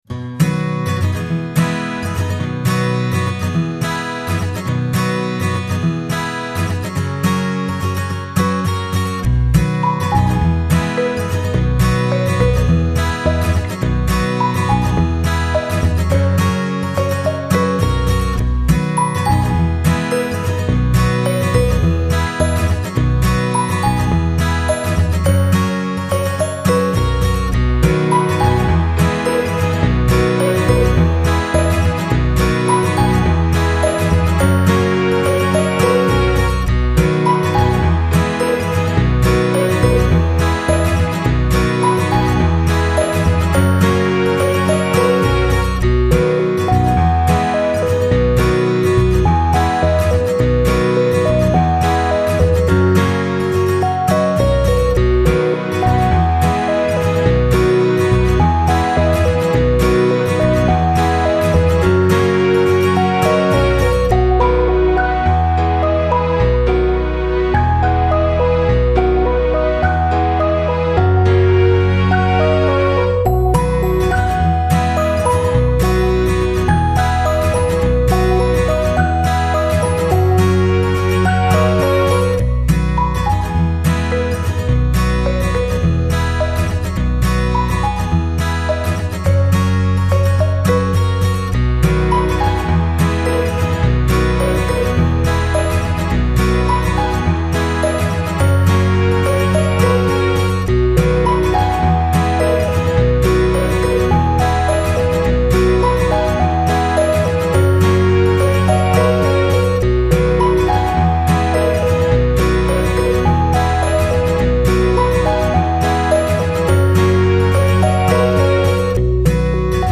это яркая и жизнеутверждающая песня в жанре поп